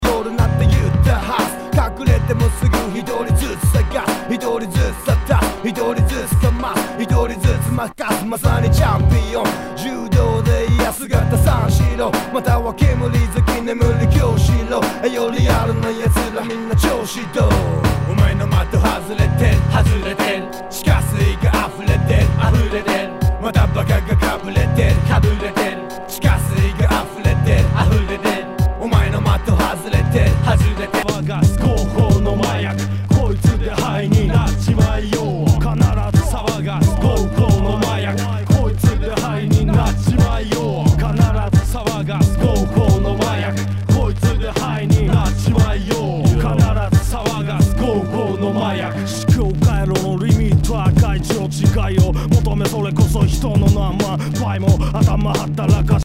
HIPHOP/R&B
全体に大きくチリノイズが入ります